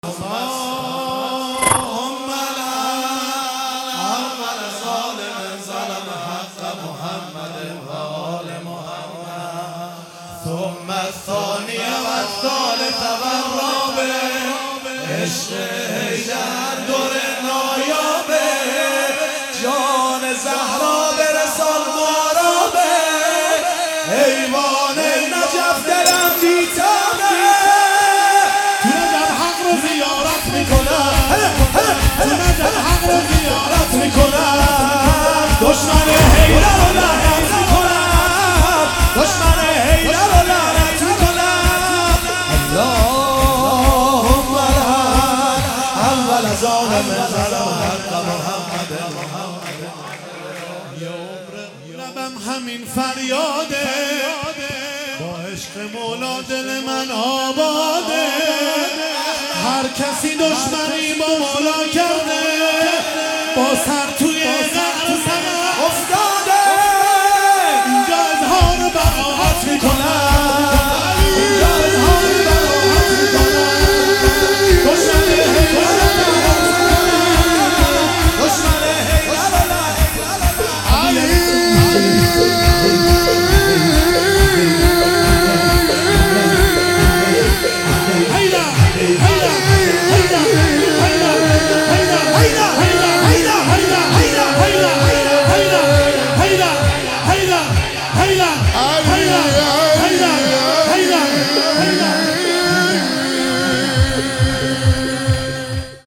میلاد حضرت رقیه سلام الله علیها